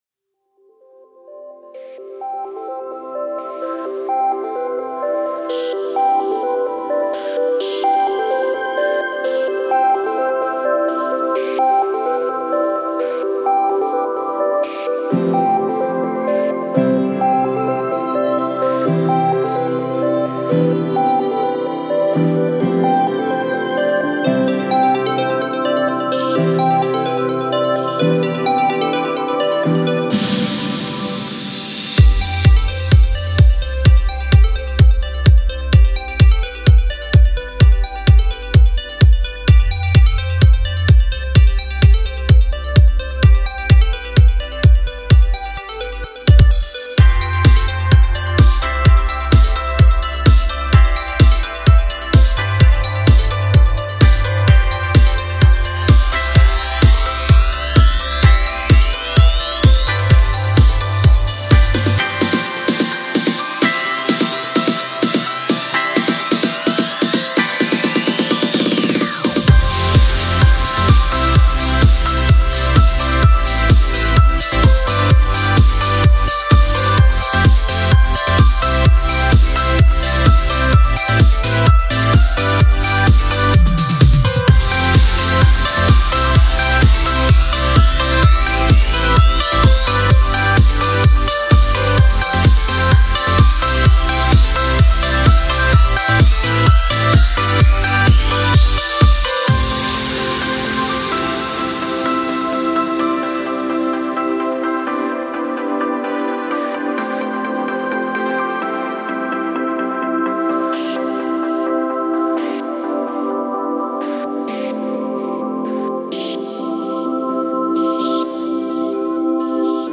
Backing Vocals
Lead Vocals